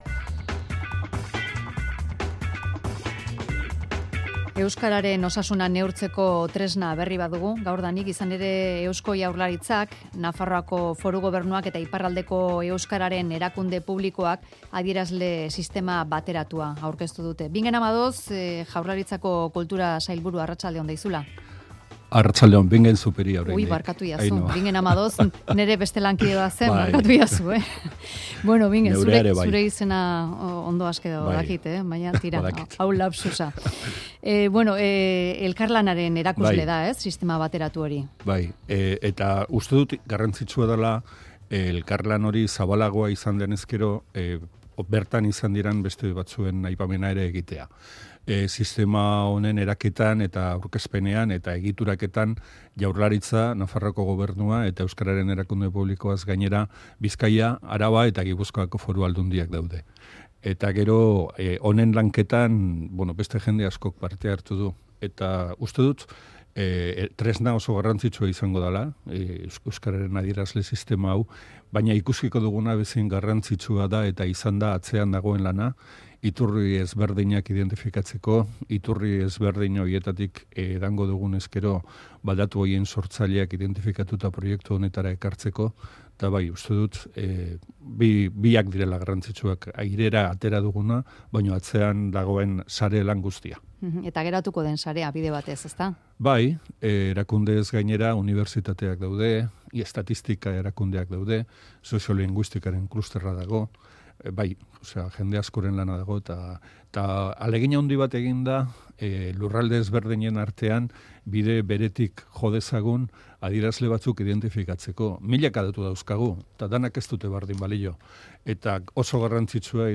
Audioa: Euskararen osasuna neurtzeko adierazle sistema bateratua sortu dute Eusko Jaurlaritzak, Nafarroako Gobernuak eta Ipar Euskal Herriko euskararen erakunde publikoak. Bingen Zupiria Kultura sailburuak eman du horren berri.